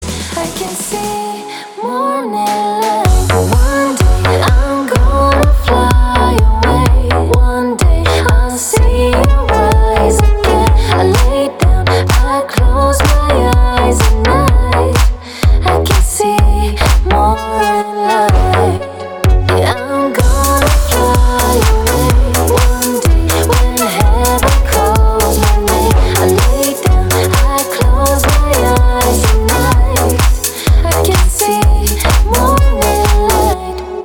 • Качество: 320, Stereo
женский вокал
deep house
Club House
future house
Cover